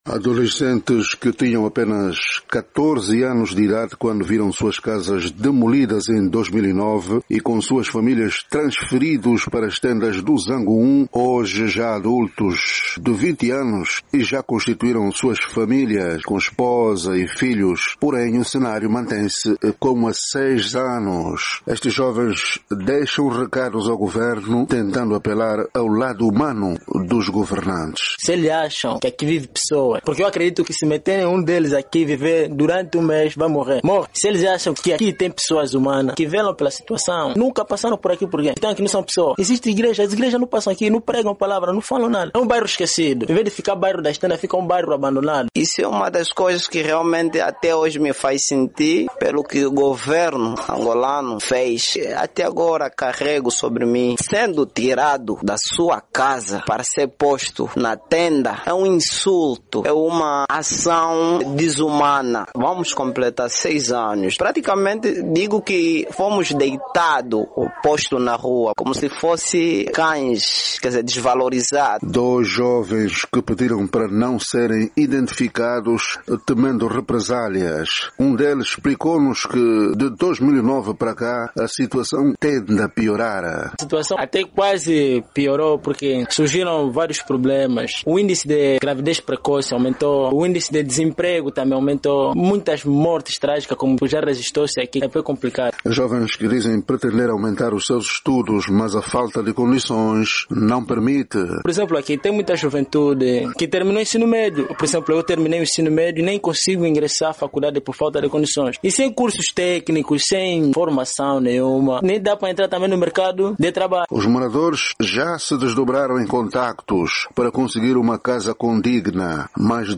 A nossa reportagem tentou mas sem obter qualquer sucesso ouvir a administração local.